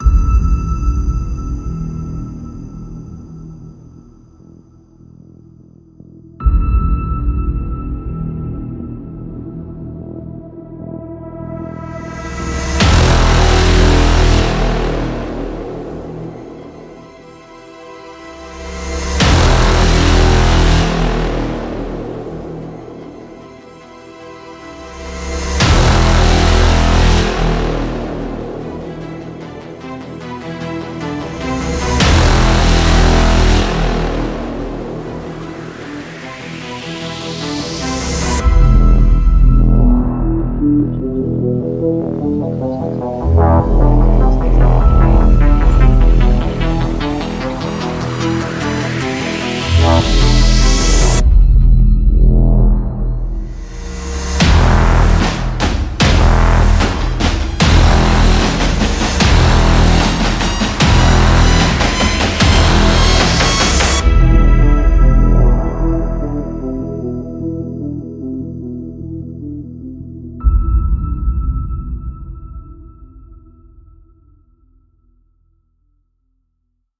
Тайная мелодия для детективного трейлера